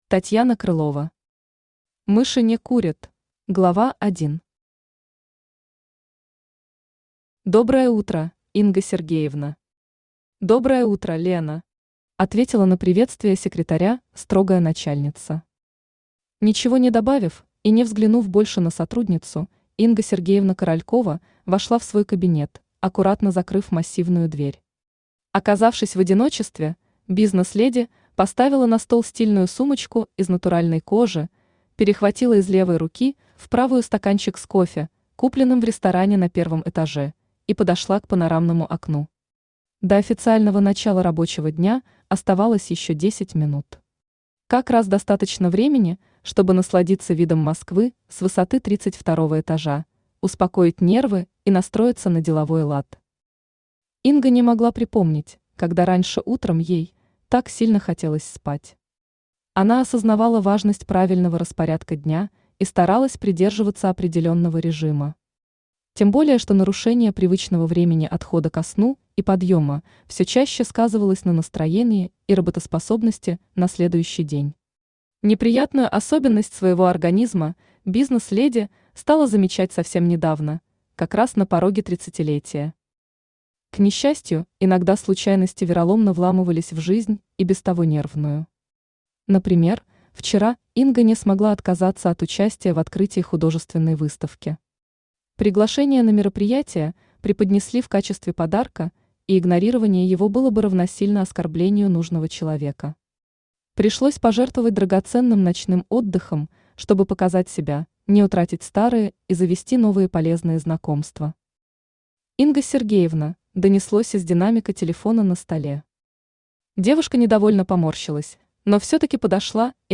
Аудиокнига Мыши не курят!
Автор Татьяна Петровна Крылова Читает аудиокнигу Авточтец ЛитРес.